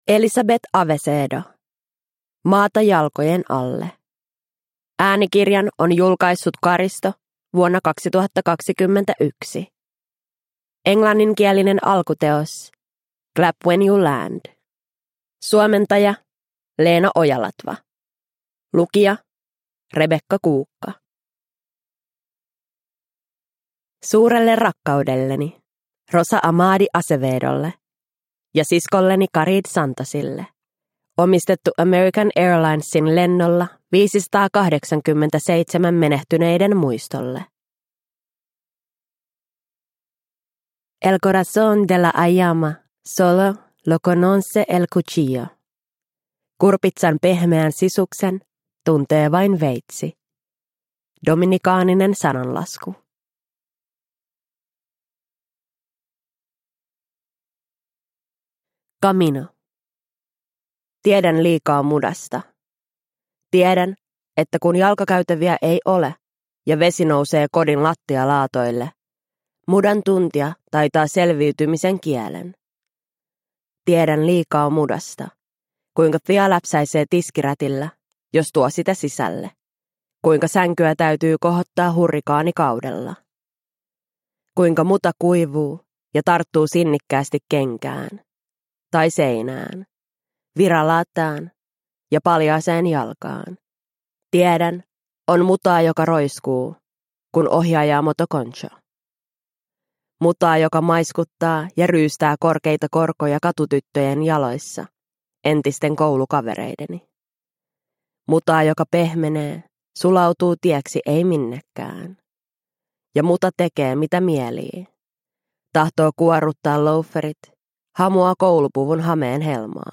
Maata jalkojen alle – Ljudbok – Laddas ner